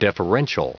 Prononciation du mot deferential en anglais (fichier audio)
Prononciation du mot : deferential